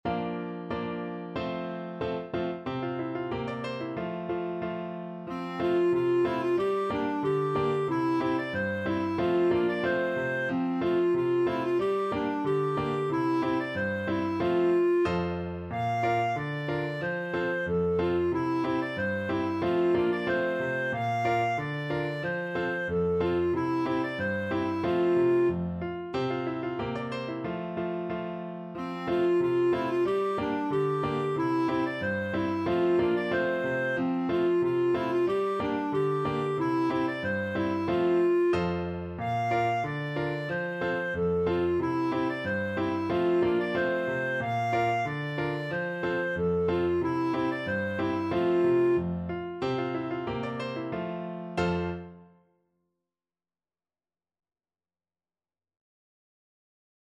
2/2 (View more 2/2 Music)
Jolly =c.92
Swiss